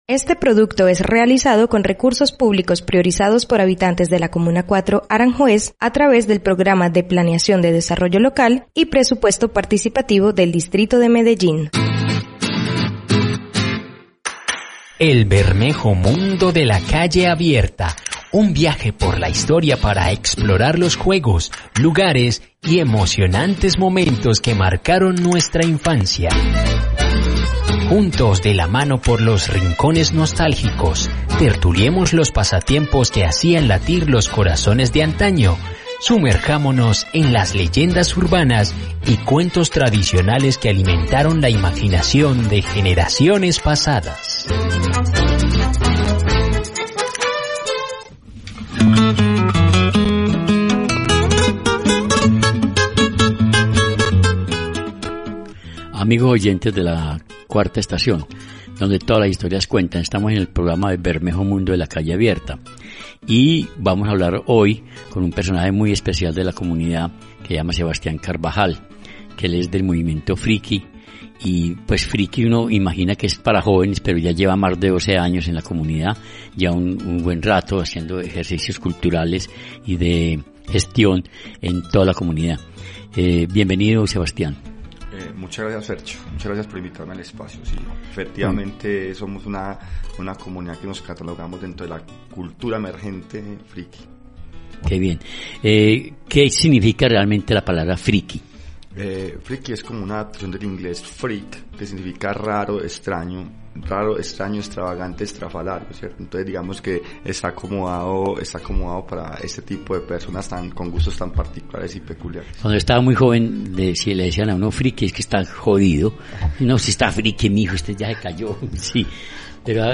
🤩Ven y disfruta de esta historia narrada